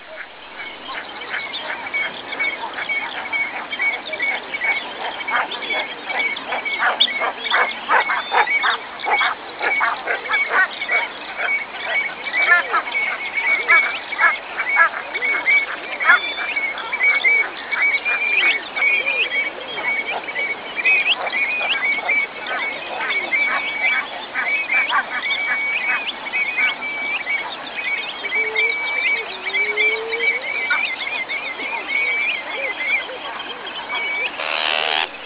Click here to hear a swamp.
rainforestswamp.wav